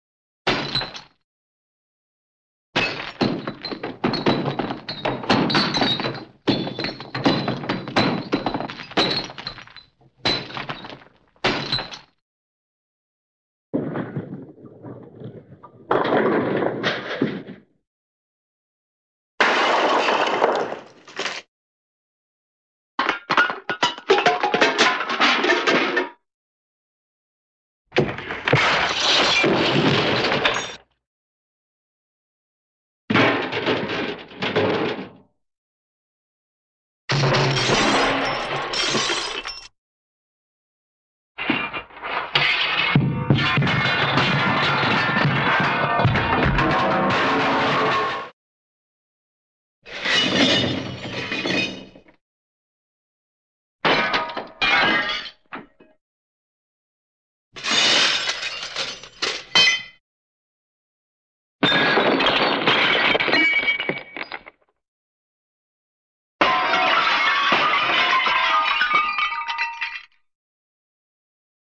27 1 Crash, Bottle Glass Bottle Drop
Category: Sound FX   Right: Personal
Tags: Cartoon